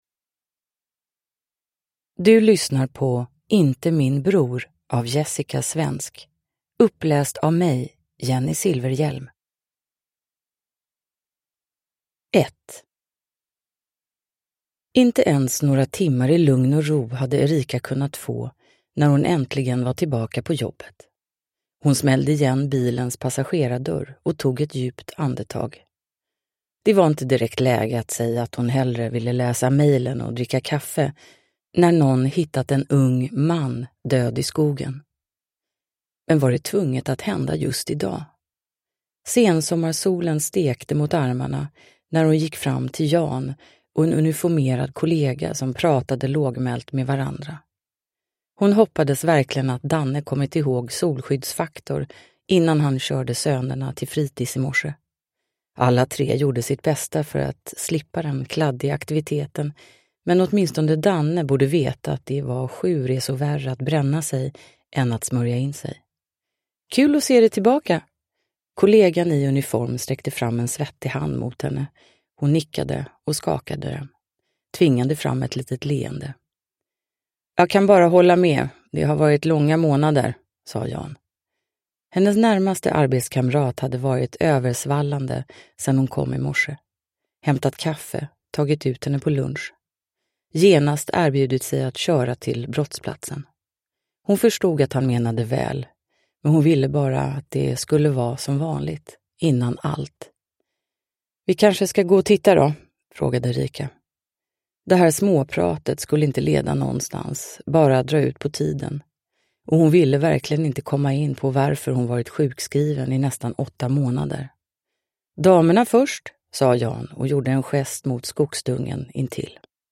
Inte min bror (ljudbok) av Jessika Svensk | Bokon